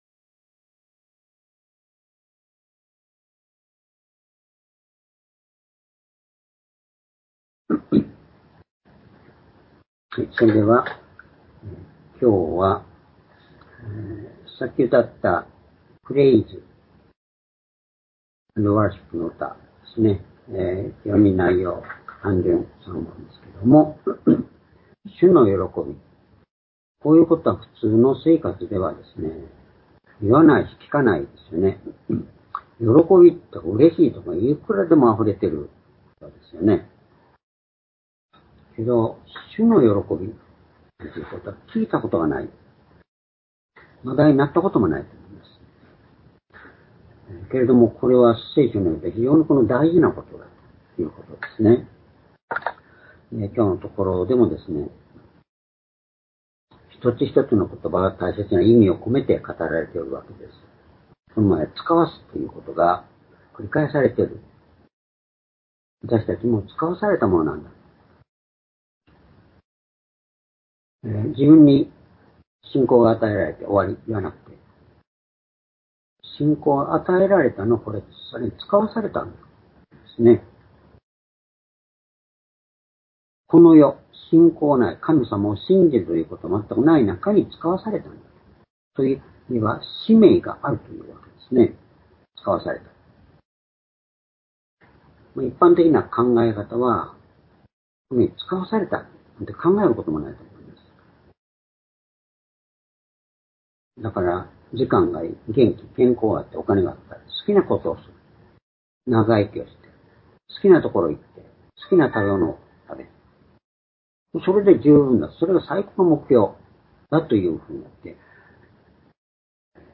主日礼拝日時 ２０２４年9月1日（主日礼拝） 聖書講話箇所 「主の喜び」 ヨハネ17章９節～１３節 ※視聴できない場合は をクリックしてください。